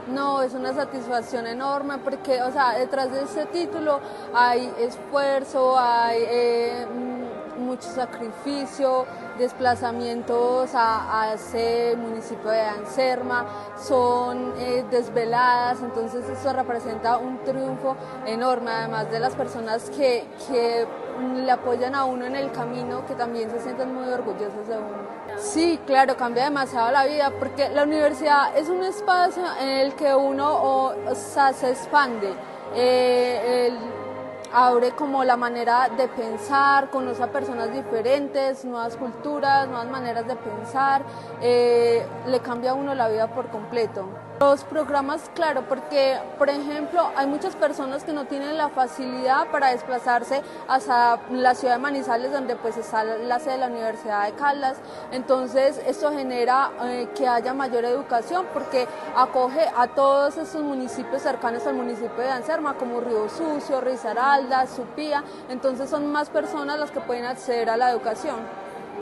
Audio estudiante en Anserma